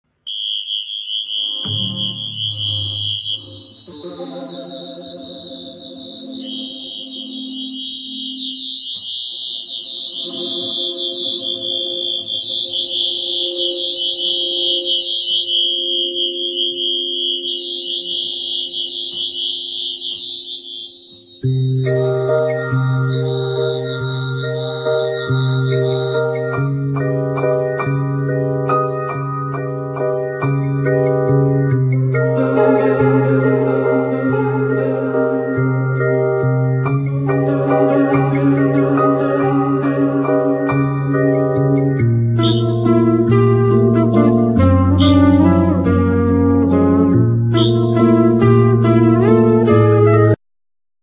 Ac.guitar,Organ,Piano,Bass,Melodica,Flute,Xylophne,Recorder